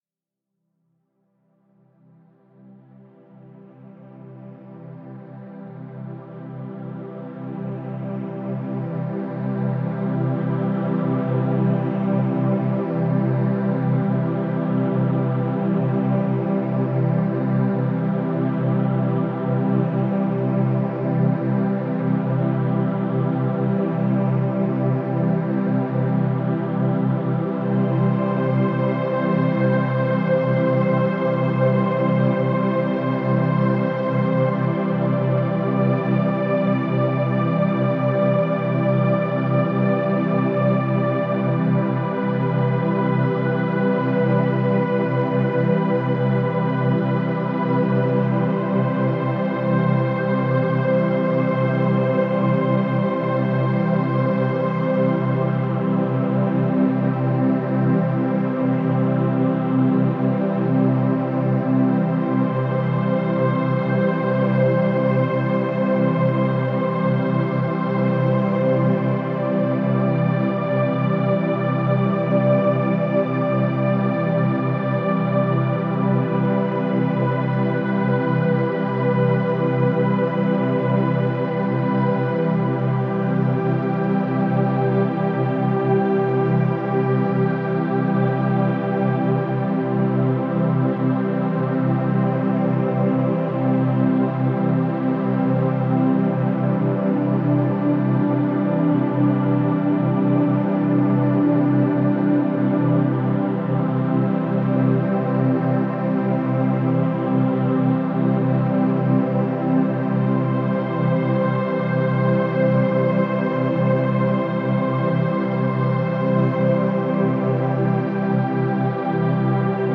Perfect for ambient, divine, dreamy.